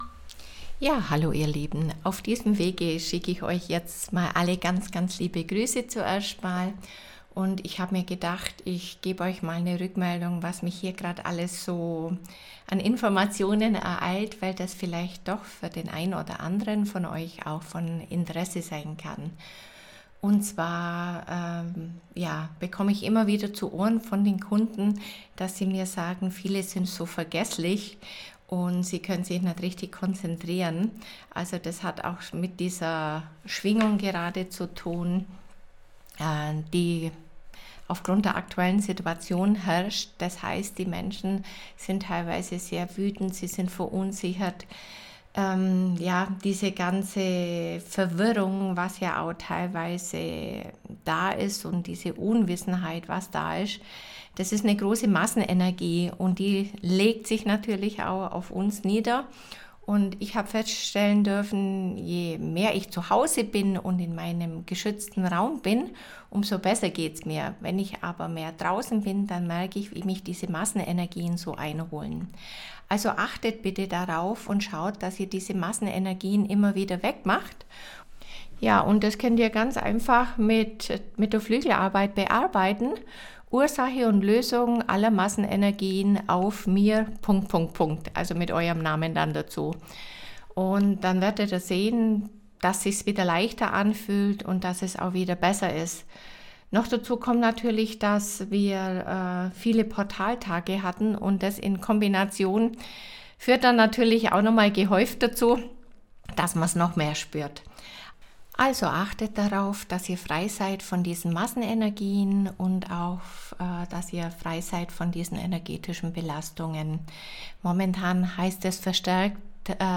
wir stellen Euch gerne kostenfrei die obige Meditation zur Verfügung.